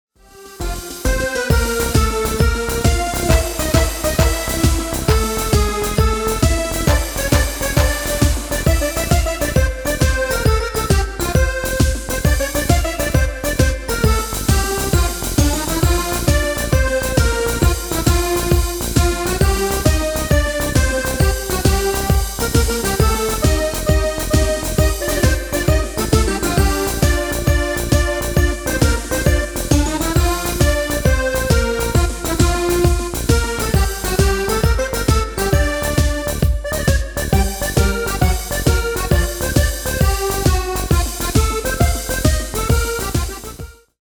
Taal uitvoering: Instrumentaal
Genre: Carnaval / Party / Apres Ski
Toonsoort: F
Demo's zijn eigen opnames van onze digitale arrangementen.